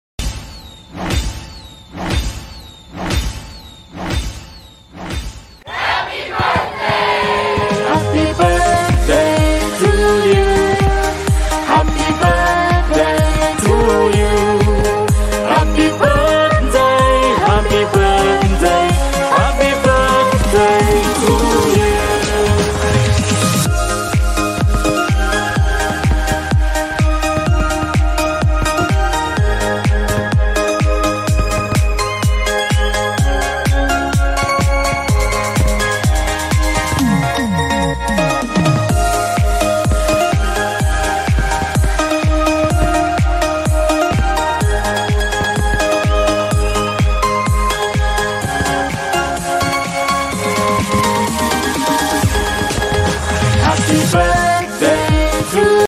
Birthday Party Mix